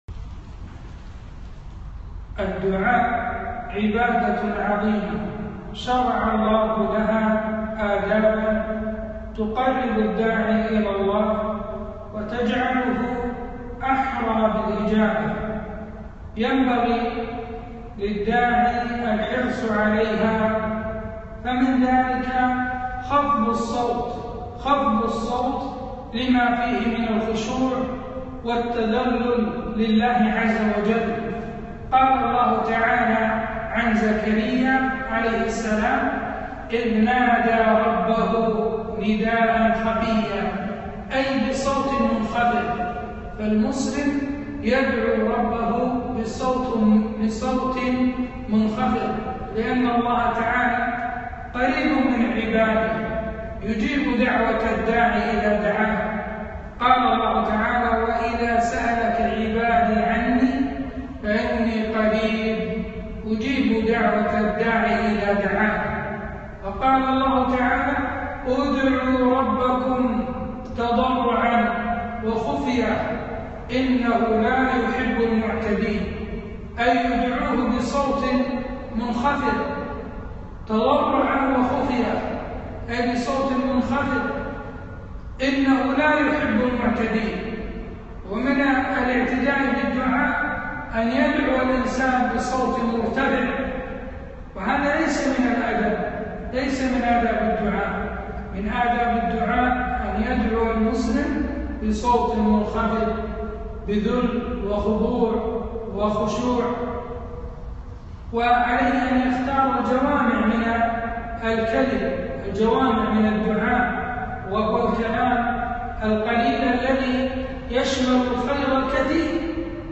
محاضرة - فضل الدعاء وآدابه